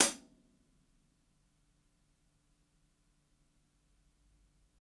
ROOMY_HH_2.wav